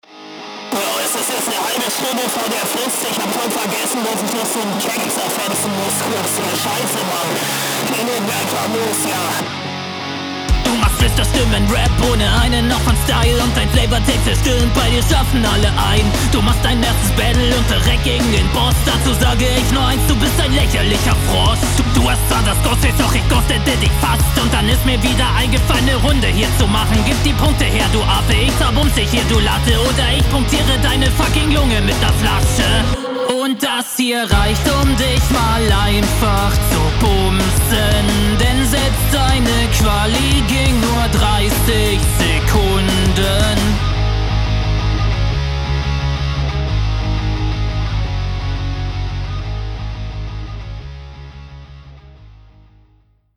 Der Beat ist cool. Das Intro auch, aber man versteht leider nichts, aufgrund des Stimmverzerrers.